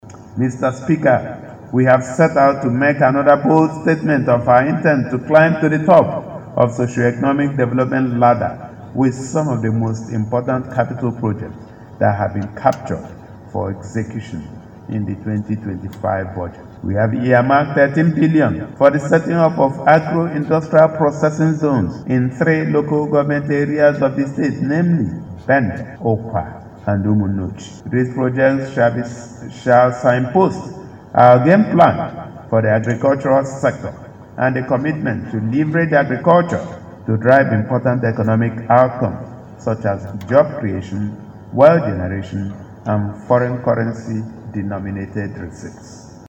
Governor Otti Presents N750billion 2025 “Budget of sustained momentum” to Abia House of Assembly